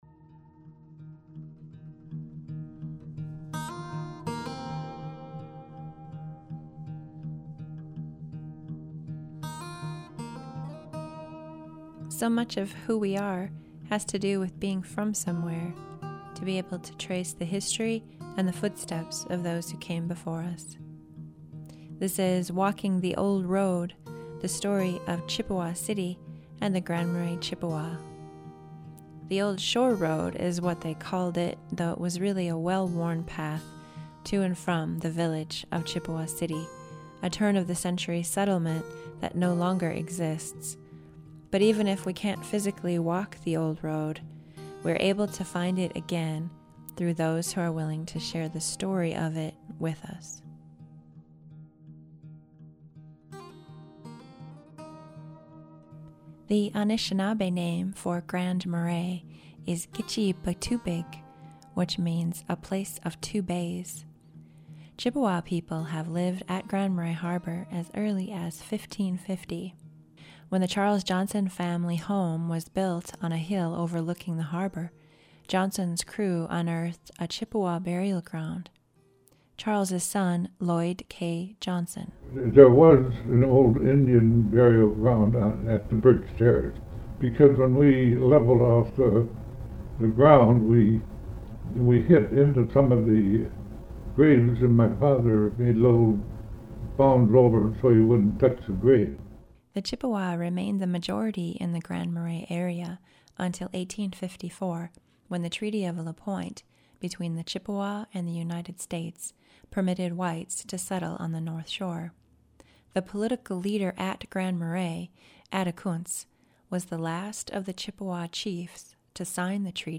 Photos for this series are courtesy of the Cook County Historical Society and portions of some achieved interviews courtesy of the Grand Portage National Monument.